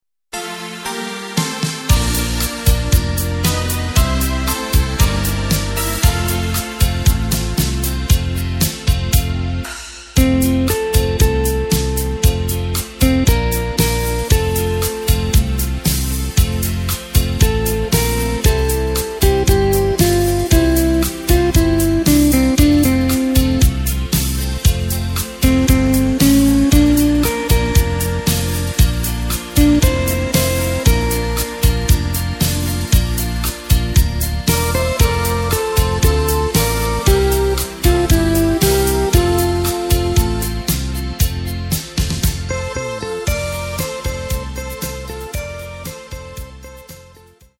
Takt:          4/4
Tempo:         116.00
Tonart:            F
Schlager aus dem Jahr 2006!